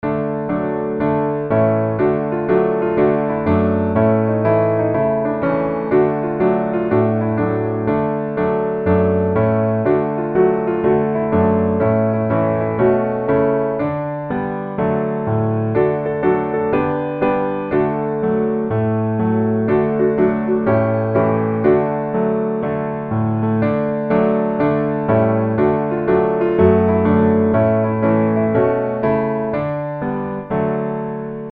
Gospel
E Majeur